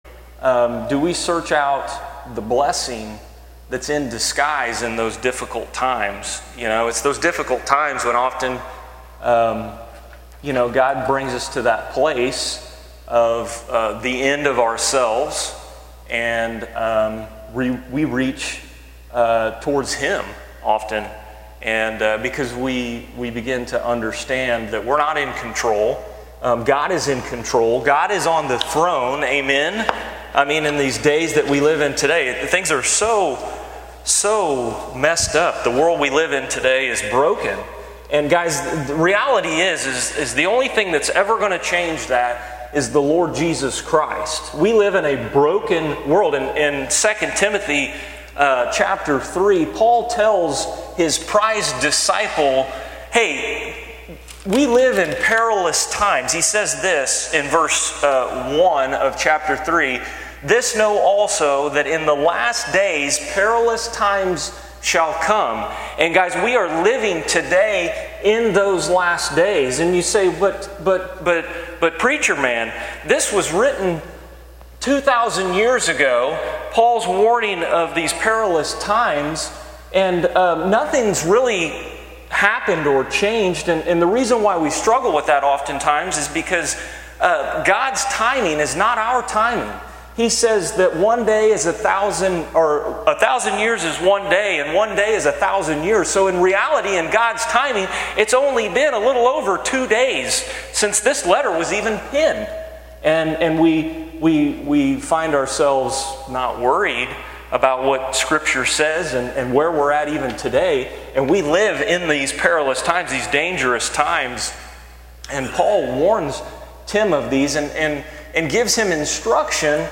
Special Message